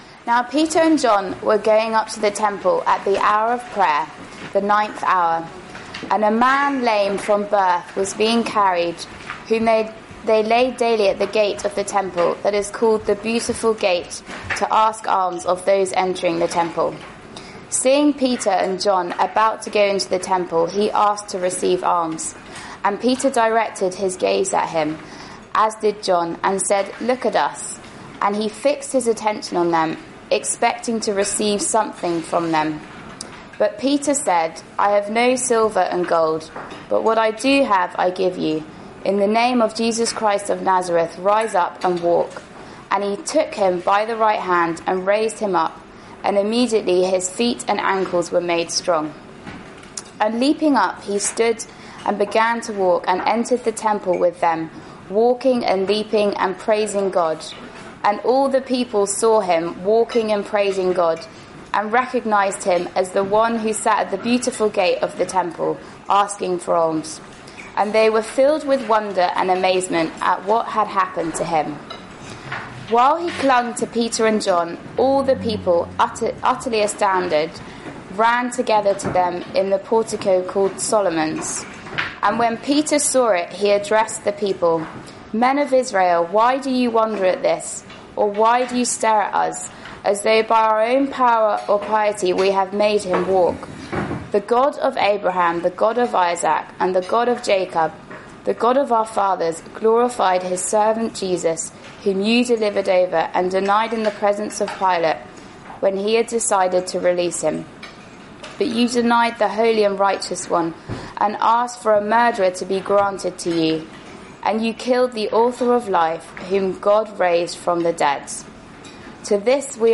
given at a Wednesday meeting